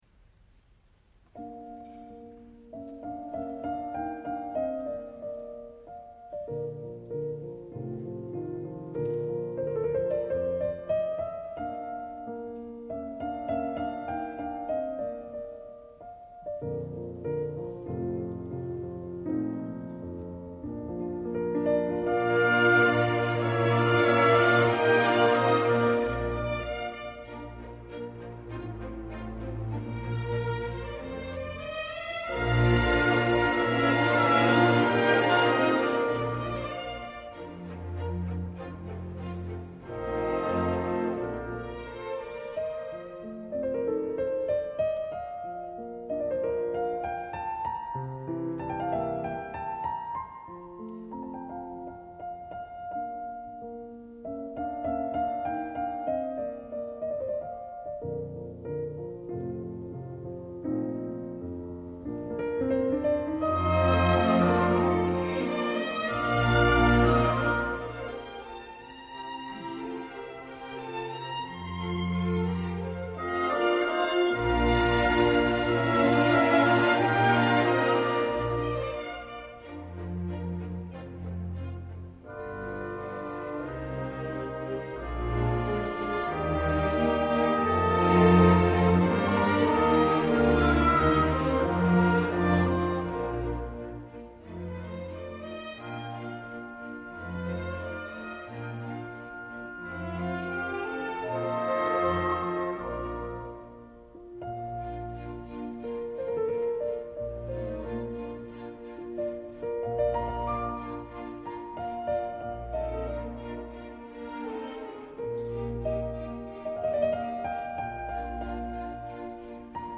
No us amoïneu si entre tanta idíl·lica tendresa, la música s’agita i veieu com el piano s’enfila aïrat en nervioses escales ascendents, no trigareu gaire a reprendre l’èxtasi amb l’emotiva melodia inicial, que acabarà de manera preciosista, amb uns arpegis que es fondran en la més delicada de les perfeccions musicals que hom pugui imaginar.
Els intèrprets, com ja anuncio a l’encapçalament, són la sempre miraculosa Maria Joao Pires i Bernard Haitink, però aquest cop l’orquestra no serà la LSO, sinó la  Symphonieorchester des Bayerischen Rundfunks (és a dir l’Orquestra Simfònica de la Radiodifusió Bavaresa) i el resultat com us podeu imaginar, és alhora que terapèutic, meravellós.
El concert es va celebrar el dia 20 de gener de 2012 a la sala Gasteig de la Philharmonie a Munic.